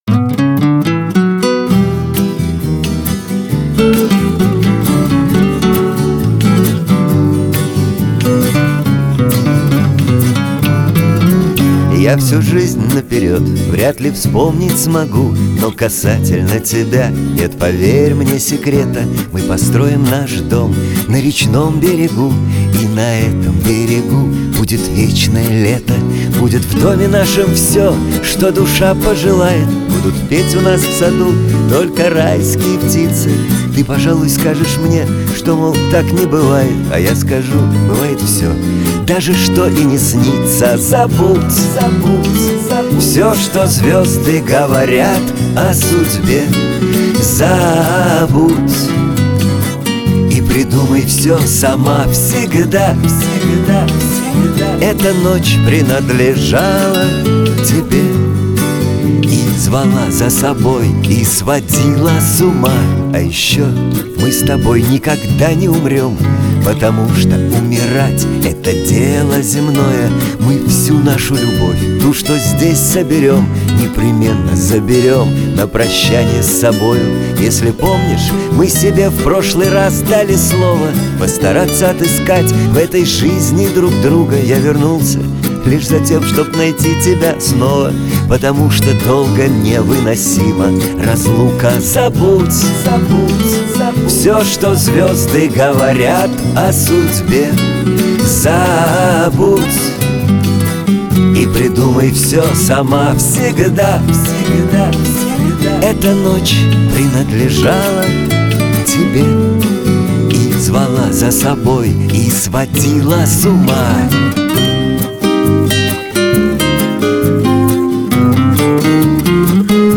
Главная ➣ Жанры ➣ Шансон. 2025.